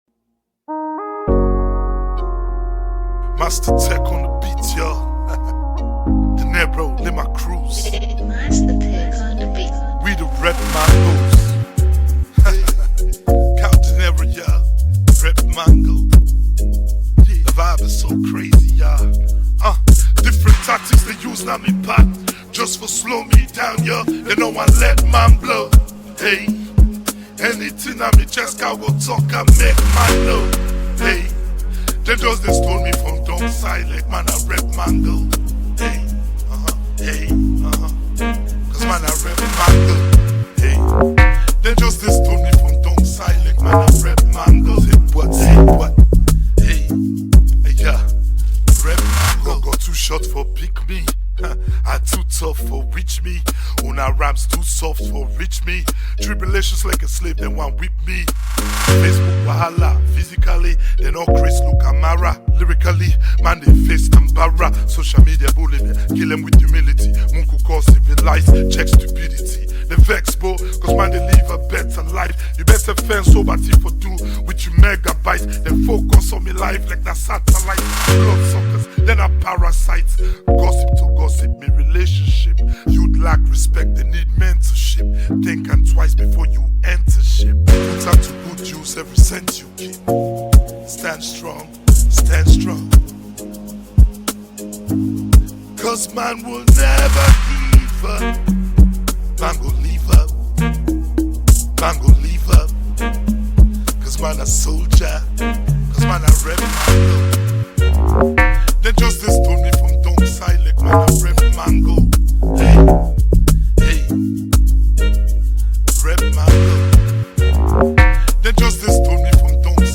bad-man song
the rapper spits some wild verses
Imagine a Rap song on an amapiano beat, He is a legend.